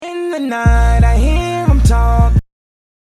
Autotune, vormals entwickelt zur Korrektur einzelner missratener Tonhöhen bei detonierenden Gesangsartisten, heute durch Überdrehen der Einstellungen als probates Mittel zum Aufpolieren bei Nichtskönnern und zum Aufhübschen von Banalitäten eingesetzt.
Auto-Tunesnippet.mp3